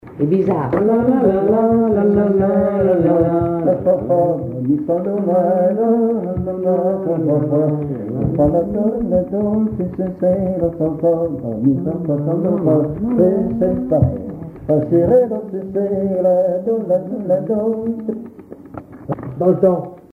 Polka chantonnée
danse : polka
Pièce musicale inédite